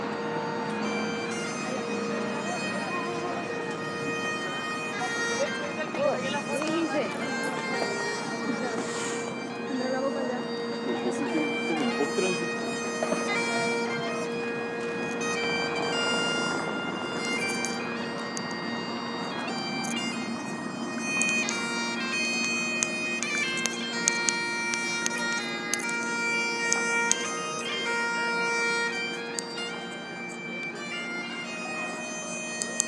Edinburgh bagpipes